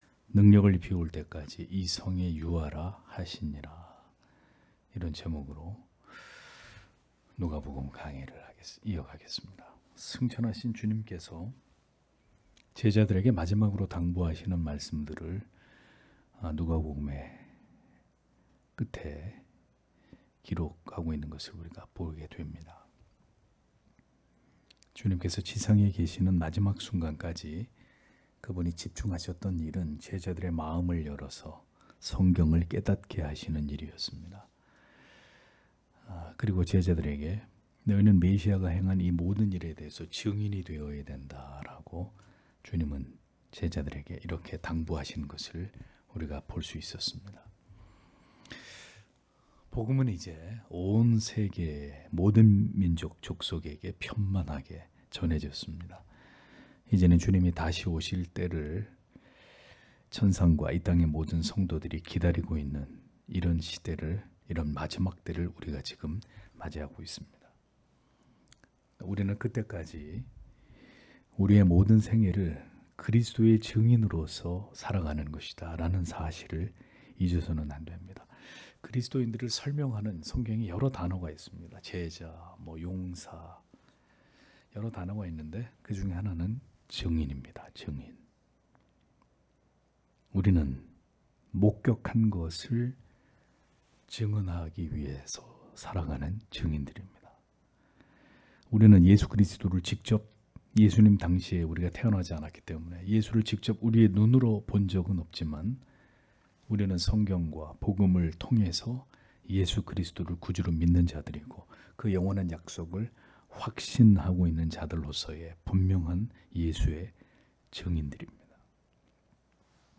금요기도회 - [누가복음 강해 188] '능력을 입히울 때까지 이 성에 유하라 하시니라' (눅 24장 49- 53절)